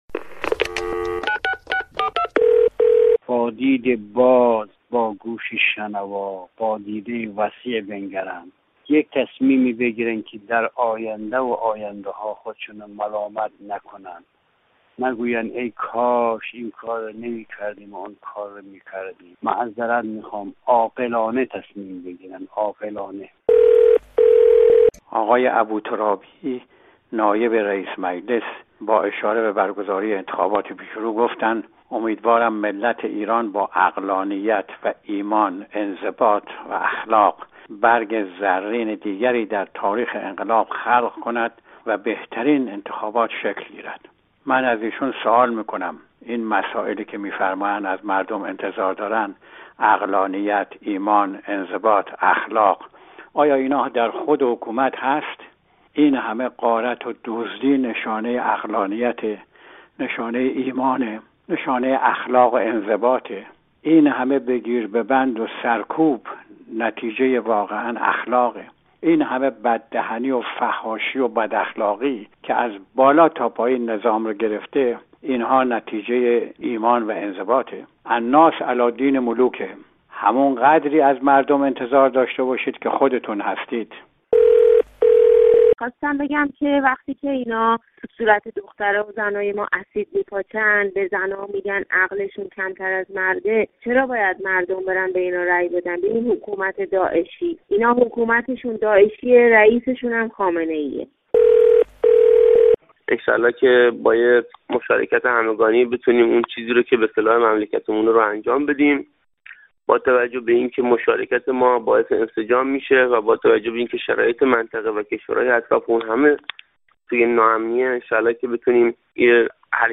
دیدگاه برخی شنوندگان رادیو فردا در مورد انتخابات هفتم اسفند